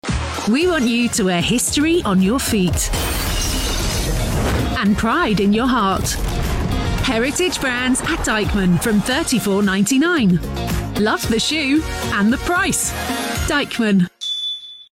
Deichmann Footwear Commercial
English - United Kingdom